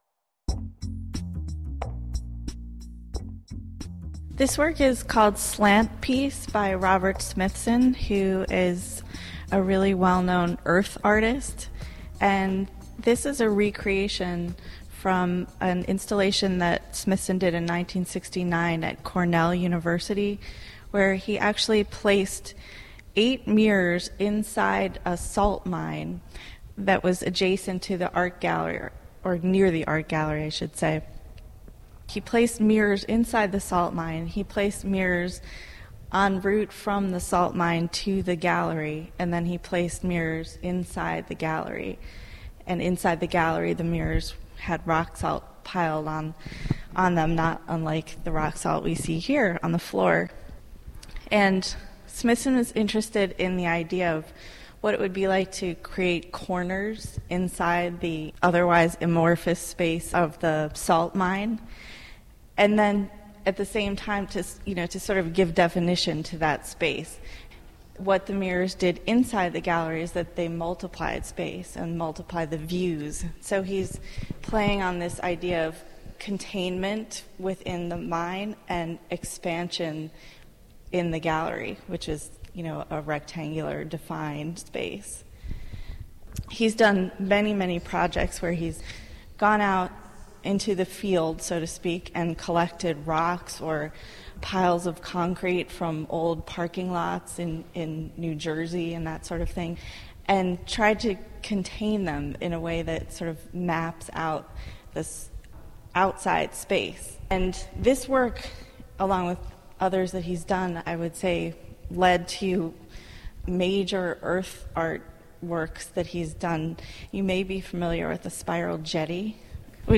These podcasts are taken from conversations in the Akron galleries